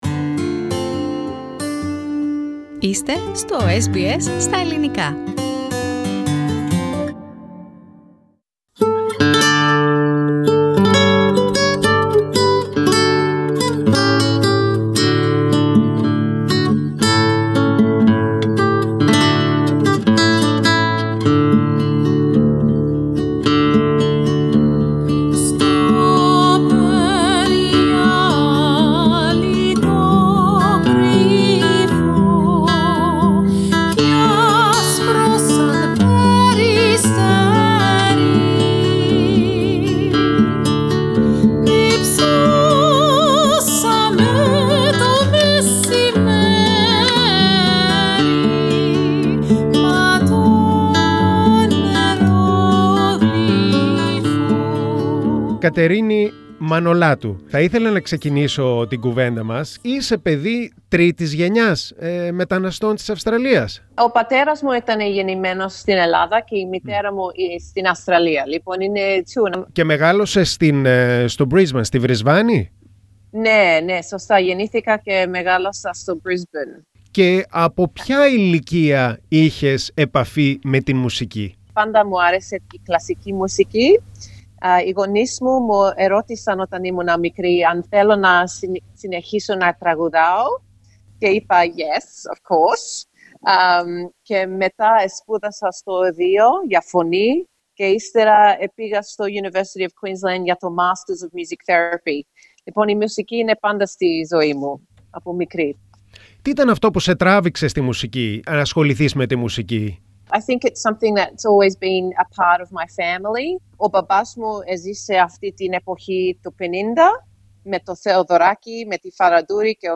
Greek-Australian mezzo-soprano